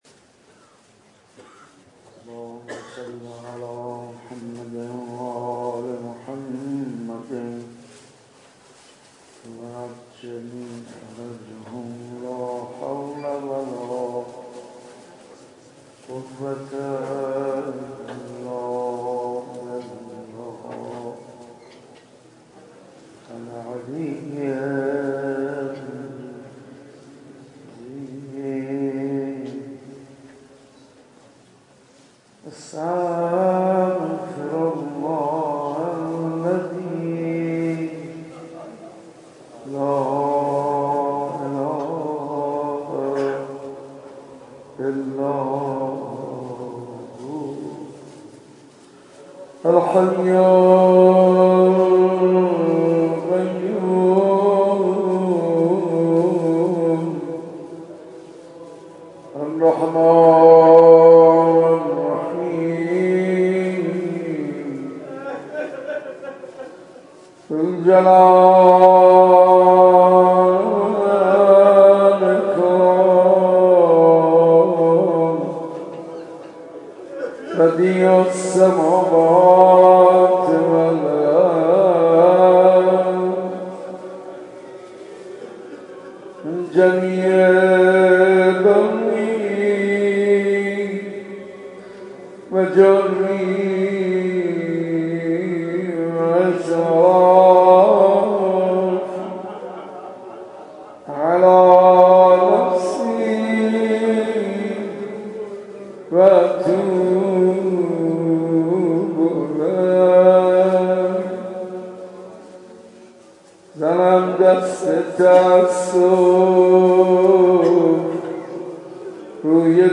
صوت/ دعای کمیل حاج منصور ارضی، سحرگاه 9 اسفند کد خبر : ۲۳۰۸۱ عقیق: مراسم دعای کمیل حرم سیدالکریم سحرگاه گذشته برگزار شد. در این مراسم با حضور مردم شب زنده دار حاج منصور ارضی به دعا خوانی پرداخت.